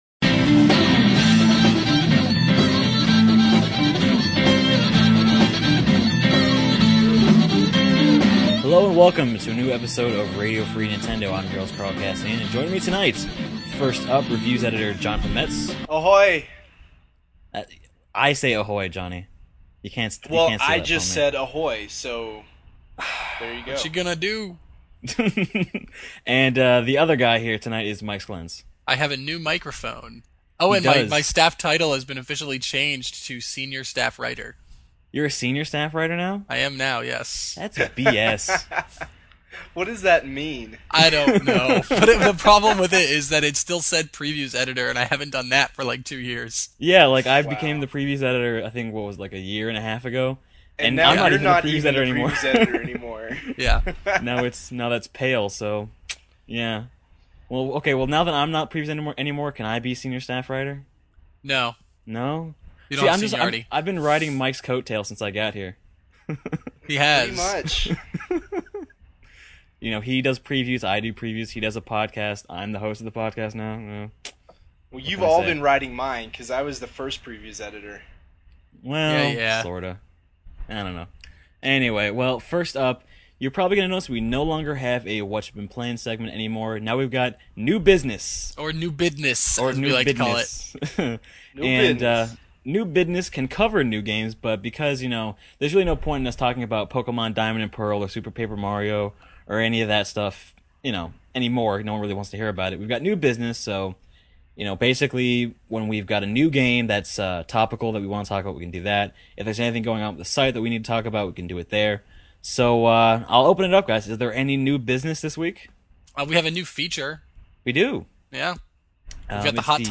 So we cut the fat for this week's iteration, including the death of Whatcha Been Playin' (in favor of the very-sexy "New Business"), as well as cutting an entire co-host (only three voices this time). Still, at 45 minutes, there's a lot to enjoy here, from the first ever discussion of Nintendo World Report's Hot Topic to the news about four different Smash Bros. control schemes, and even a teaser about what to expect next week (hint: it will be good times).